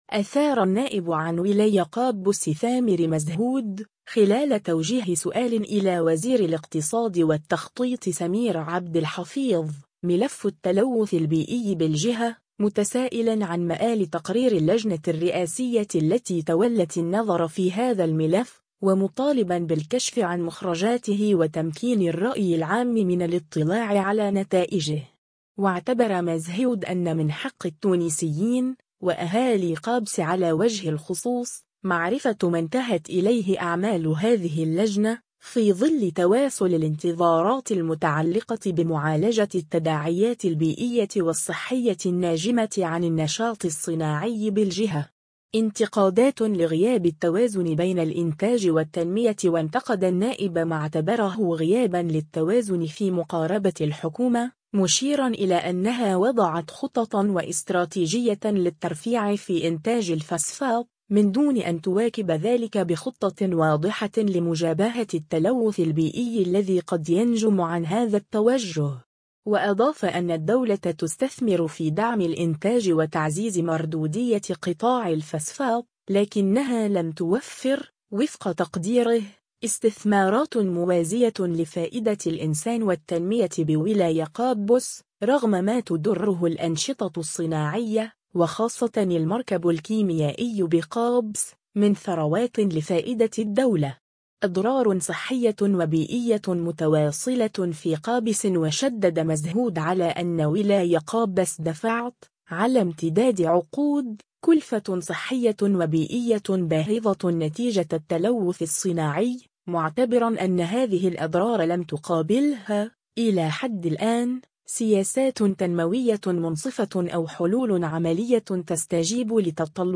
أثار النائب عن ولاية قابس ثامر مزهود، خلال توجيه سؤال إلى وزير الاقتصاد والتخطيط سمير عبد الحفيظ، ملف التلوث البيئي بالجهة، متسائلا عن مآل تقرير اللجنة الرئاسية التي تولّت النظر في هذا الملف، ومطالبا بالكشف عن مخرجاته وتمكين الرأي العام من الاطلاع على نتائجه.